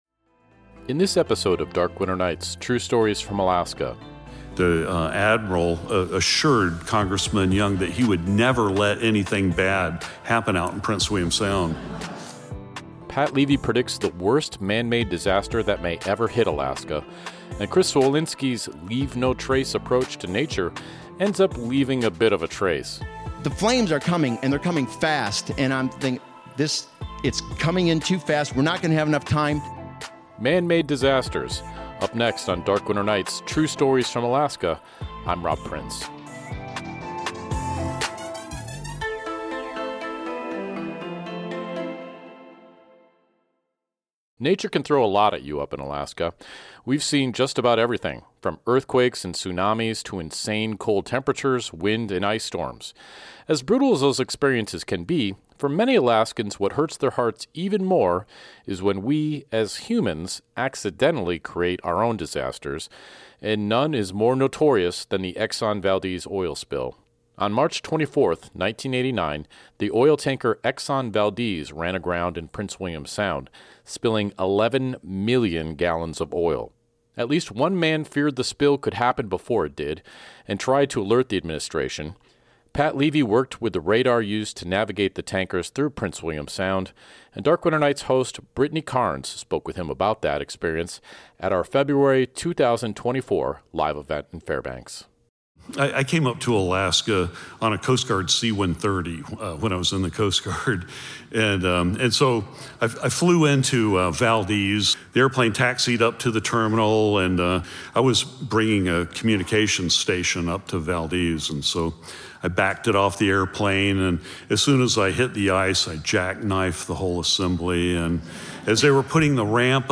Hear amazing true stories from Alaska told by the people who experienced them!